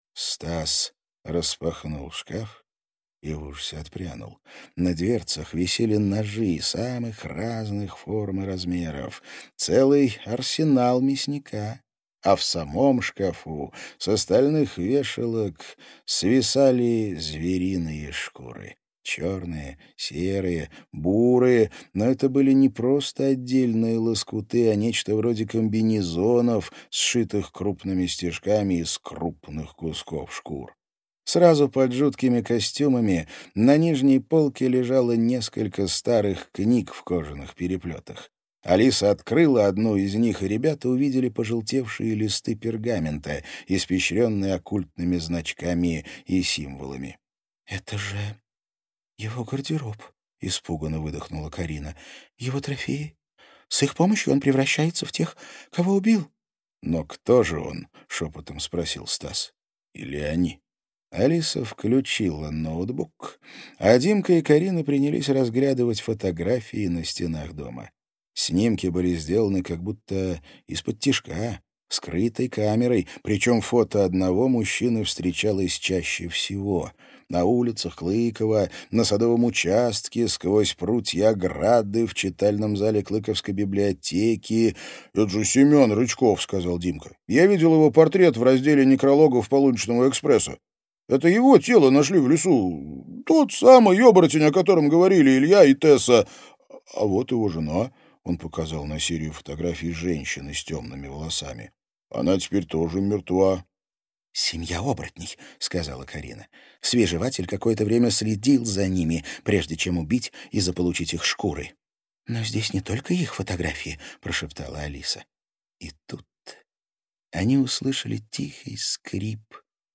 Аудиокнига Пандемониум. Время Темных охотников | Библиотека аудиокниг